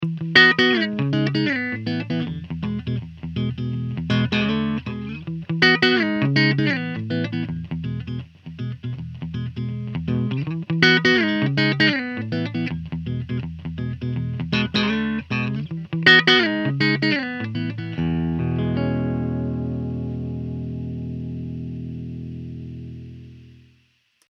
Pop rhythm